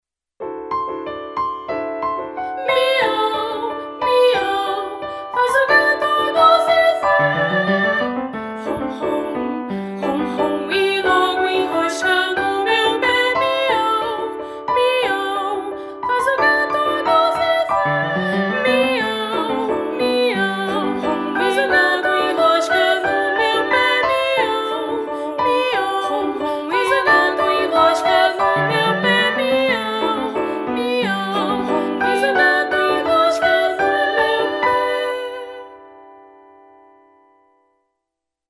Mix